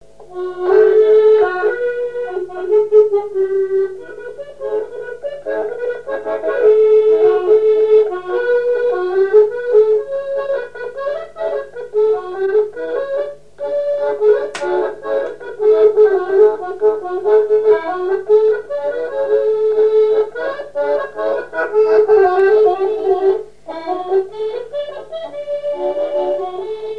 Mémoires et Patrimoines vivants - RaddO est une base de données d'archives iconographiques et sonores.
danse : valse
Pièce musicale inédite